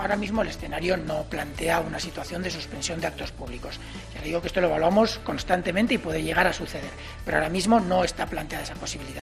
Declaraciones de Fernando Simón este viernes sobre el coronavirus